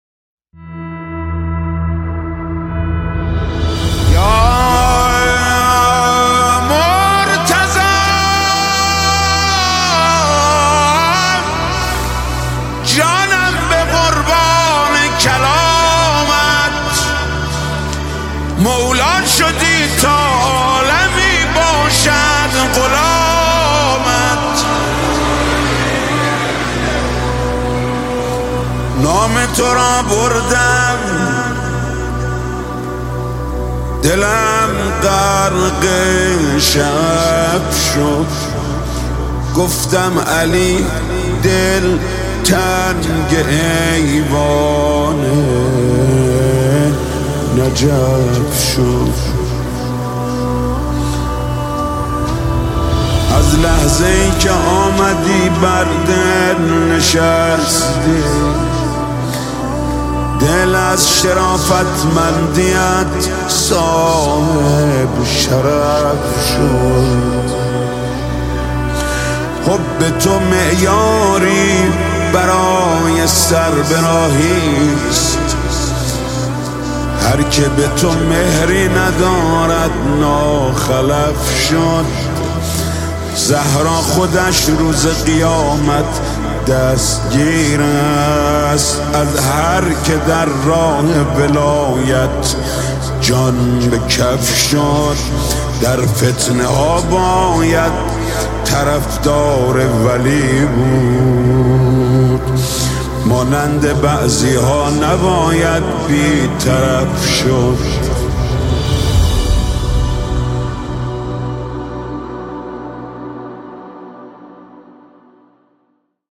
مدح امام علی (ع)